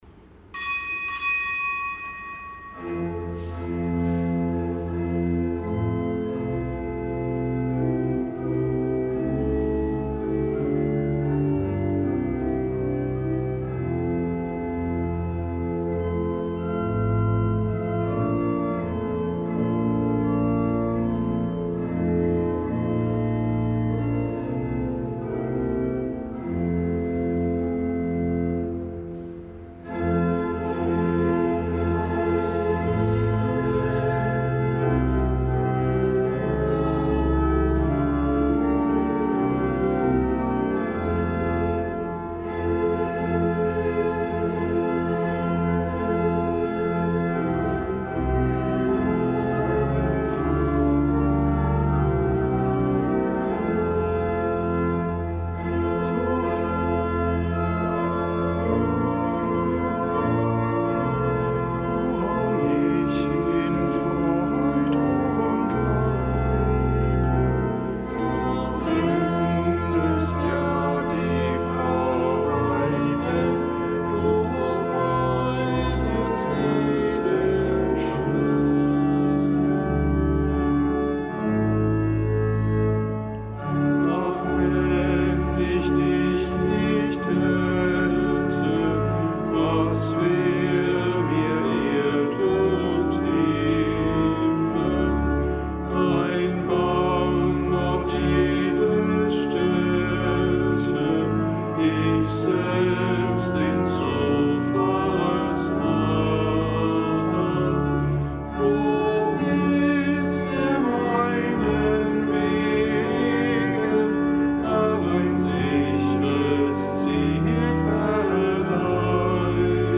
Kapitelsmesse am Freitag der vierunddreißigsten Woche im Jahreskreis